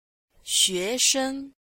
xue2sheng1-f.mp3